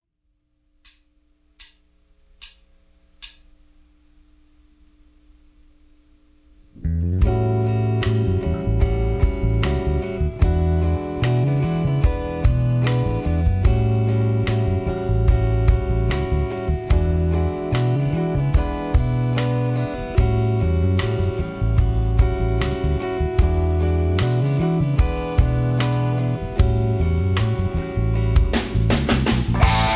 Tags: karaoke backing tracks , soundtracks , rock and roll